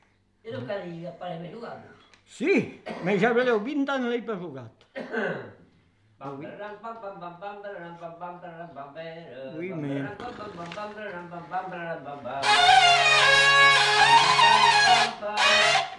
Lieu : Vielle-Soubiran
Genre : chant
Effectif : 1
Type de voix : voix d'homme
Production du son : fredonné
Danse : quadrille